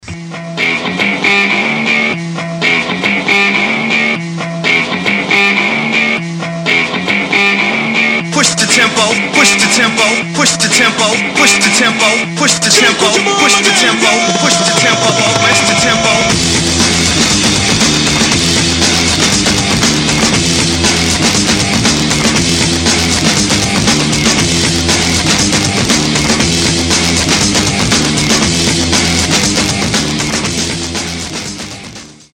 мужской вокал
громкие
энергичные
быстрые
Electronic Rock
house
breakbeat
электронный рок